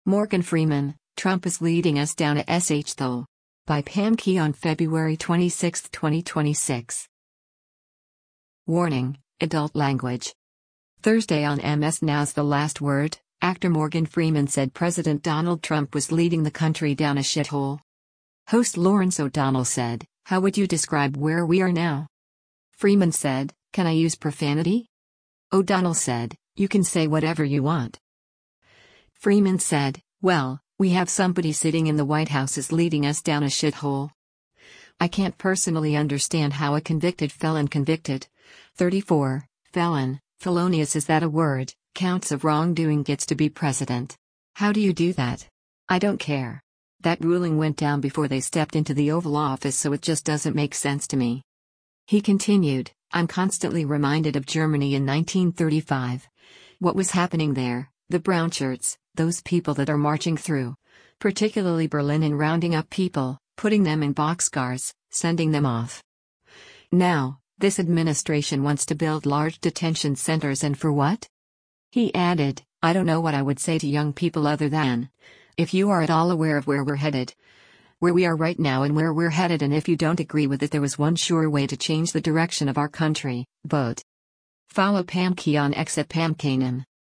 [WARNING: Adult Language]
Thursday on MS NOW’s “The Last Word,” actor Morgan Freeman said President Donald Trump was leading the country “down a shithole.”